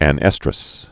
(ăn-ĕstrəs)